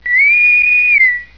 Intercom wav
tos-intercom.aif